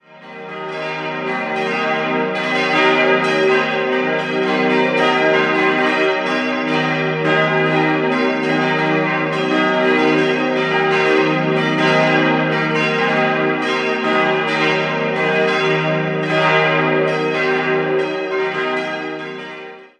Bis zur endgültigen Fertigstellung vergingen danach noch einige Jahre. 5-stimmiges Geläute: es'-g'-b'-c''-es'' Die Glocken wurden 1962 von Rudolf Perner in Passau gegossen.